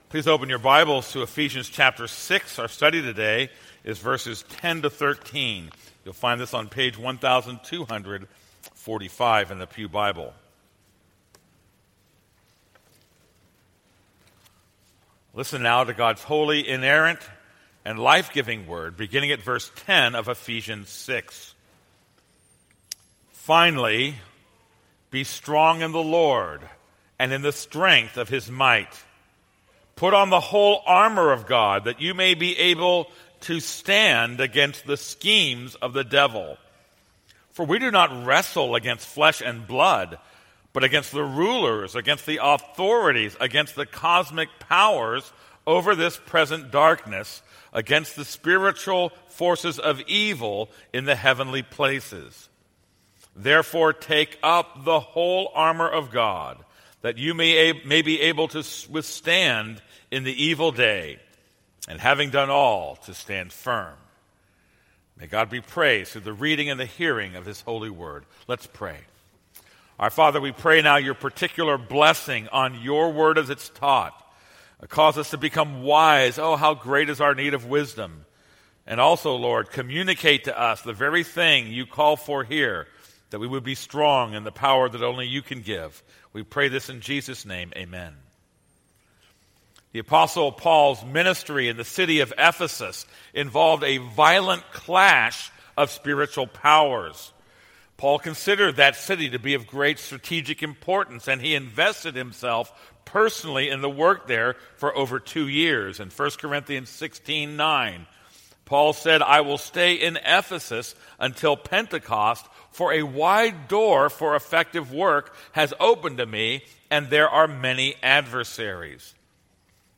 This is a sermon on Ephesians 6:10-13.